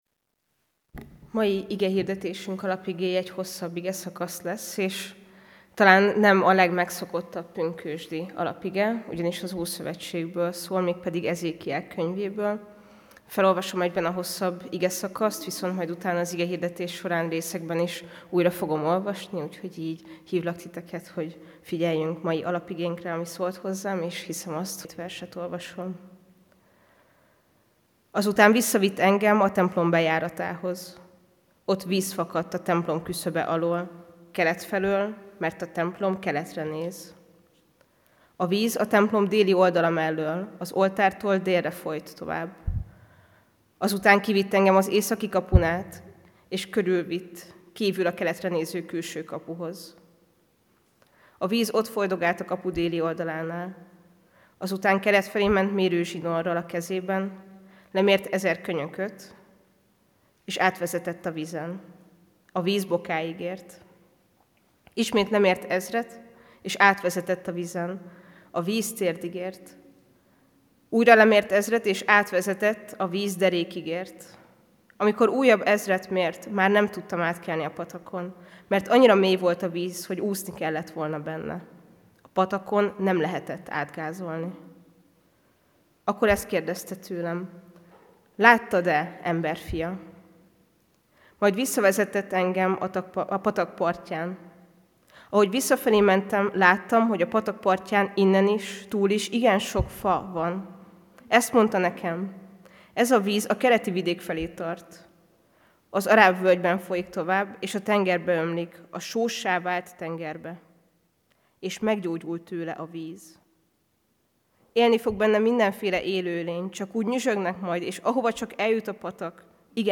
AZ IGEHIRDETÉS LETÖLTÉSE PDF FÁJLKÉNT AZ IGEHIRDETÉS MEGHALLGATÁSA
Pünkösd hétfő